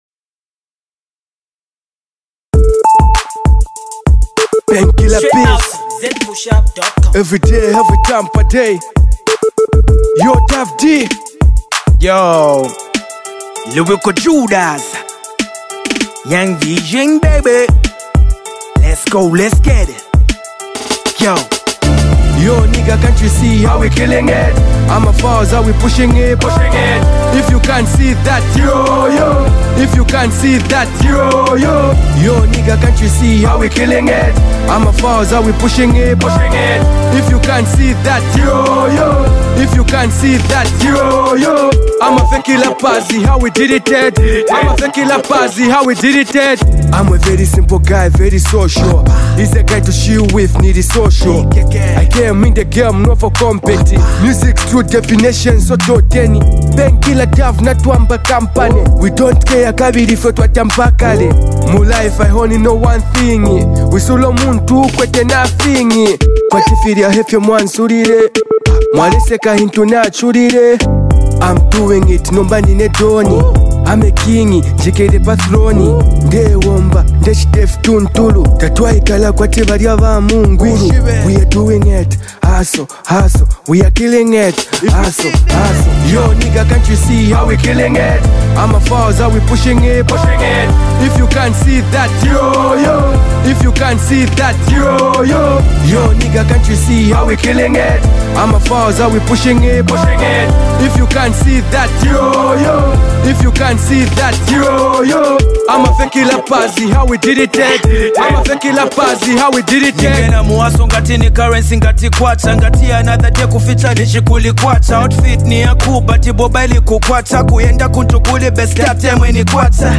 hiphop sensorial joint